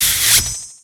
Cri de Scalpion dans Pokémon X et Y.